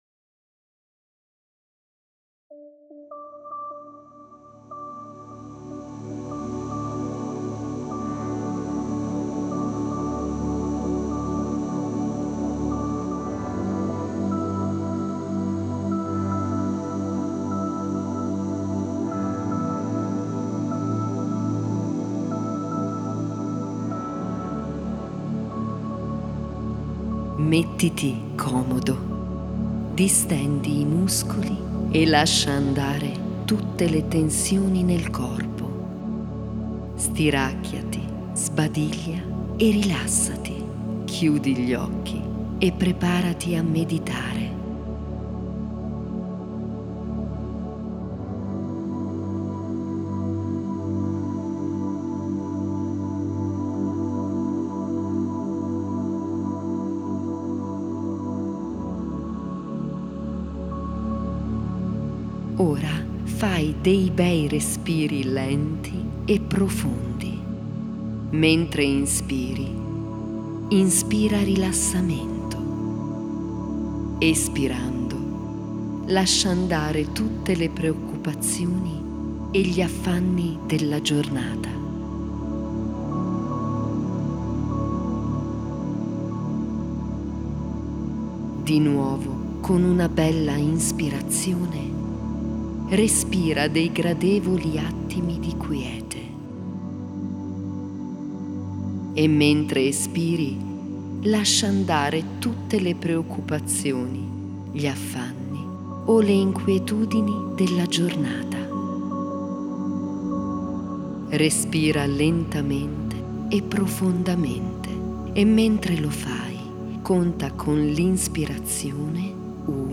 MF124DLI_Viaggio_ai_Registri_Akashici_1_meditazione.mp3